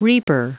Prononciation du mot reaper en anglais (fichier audio)
Prononciation du mot : reaper